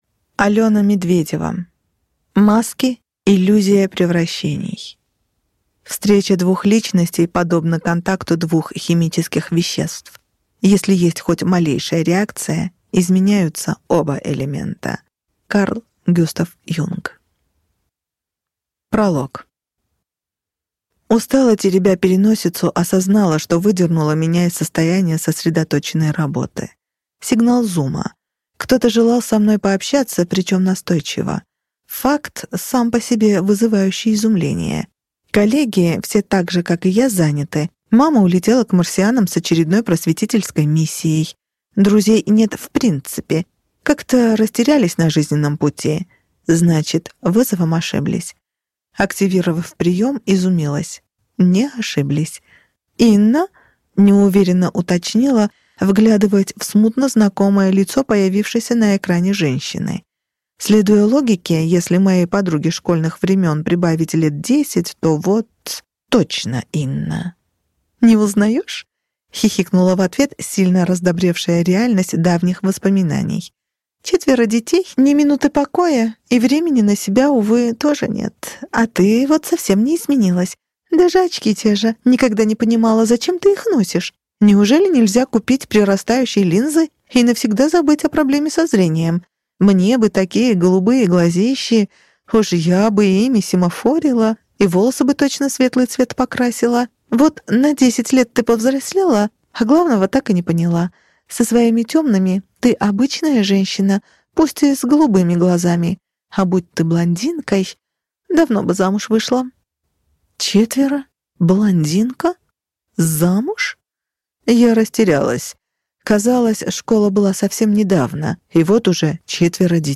Аудиокнига Маски. Иллюзия превращений | Библиотека аудиокниг